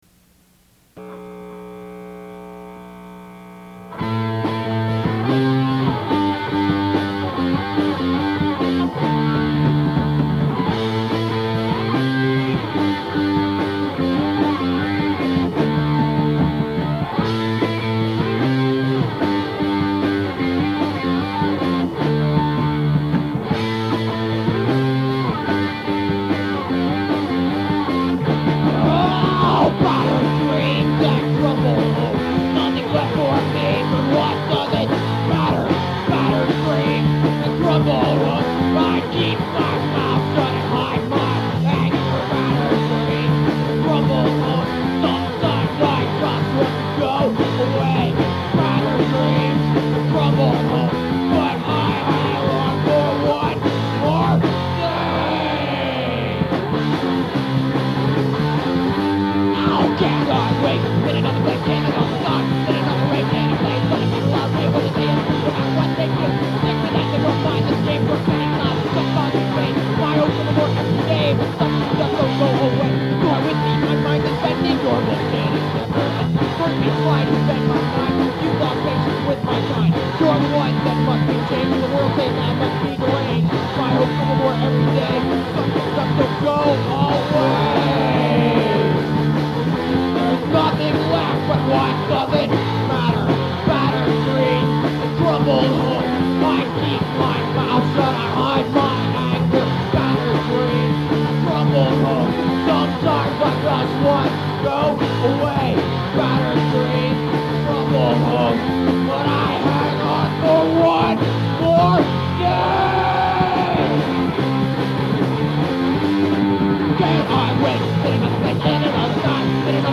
demo tape
Guitar
Drums
on bass.